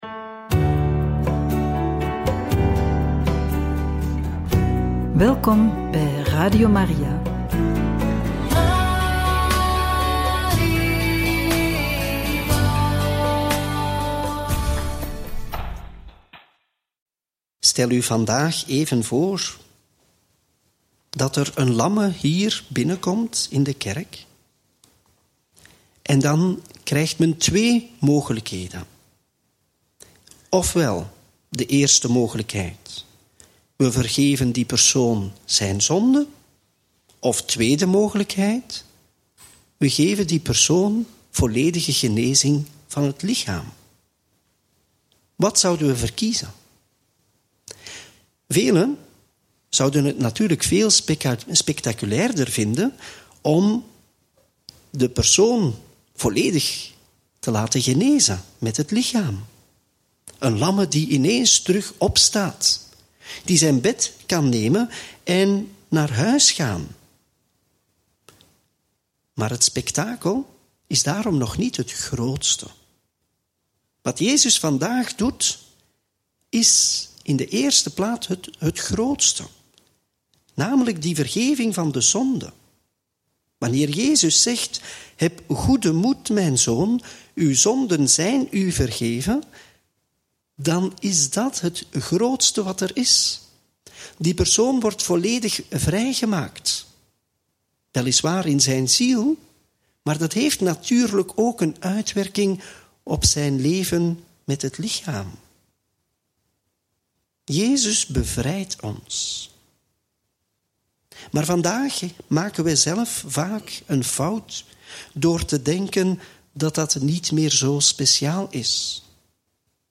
Homilie bij het Evangelie op donderdag 4 juli 2024 (Mt. 9, 1-8)